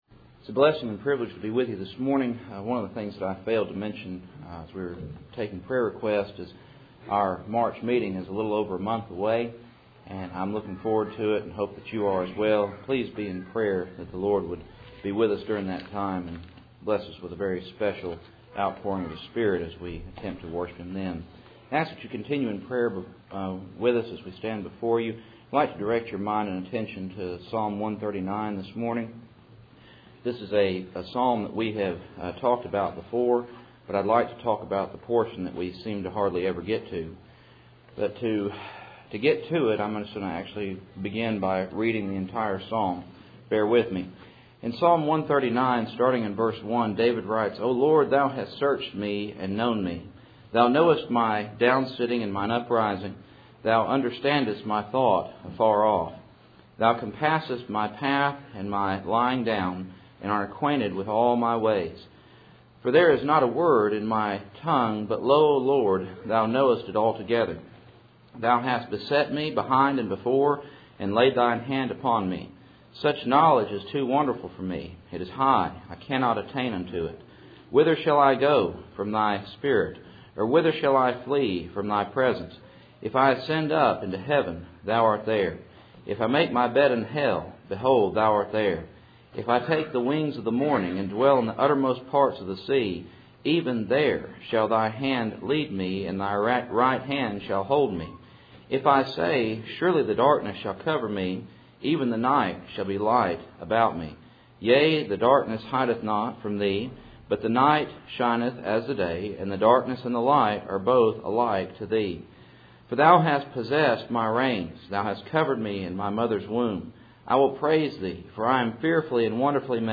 Passage: Psalm 139:1-24 Service Type: Cool Springs PBC Sunday Morning